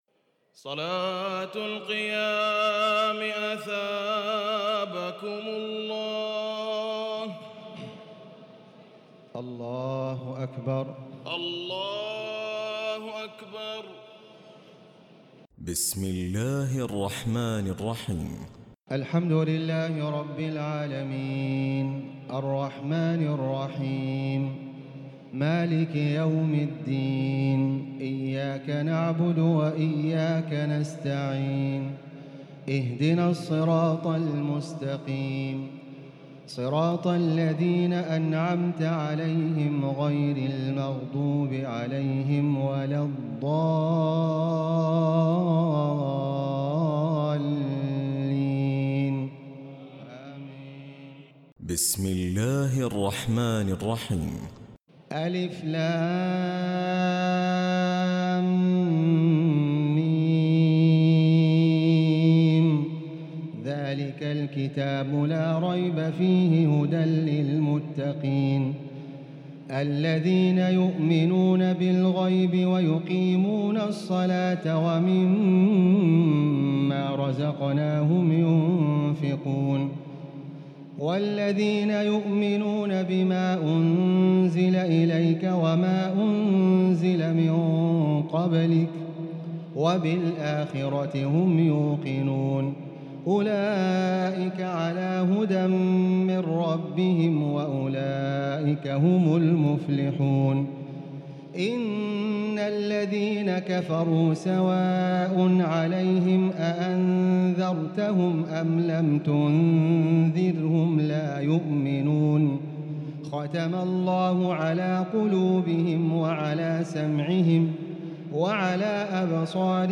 تراويح الليلة الأولى رمضان 1438هـ من سورة البقرة (1-91) Taraweeh 1st night Ramadan 1438H from Surah Al-Baqara > تراويح الحرم المكي عام 1438 🕋 > التراويح - تلاوات الحرمين